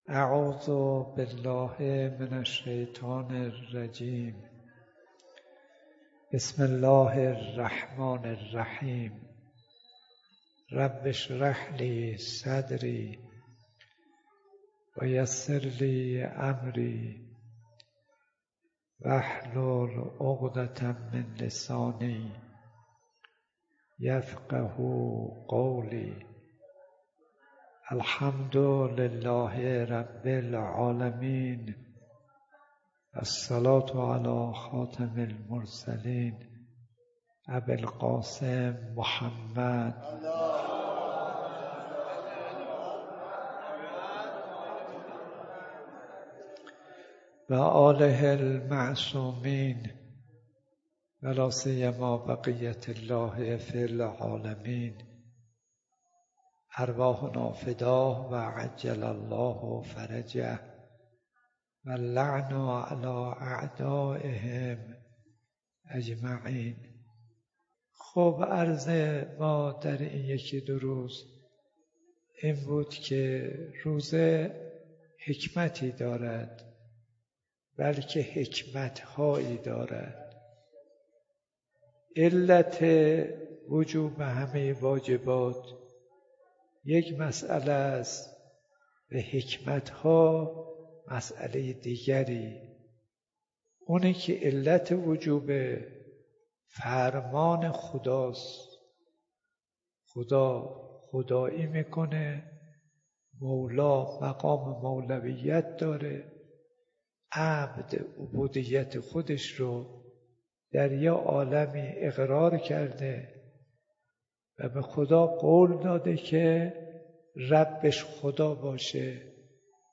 سومـیـن قـسـمت از بیـانـات ارزشــمنـد حجت الاسلام کاظـم صـدیقی با عنوان «ضیـافت الـهی» ویژه ماه مبـارک رمضـان / مـدت زمان سـخنـرانـی : 26 دقیقه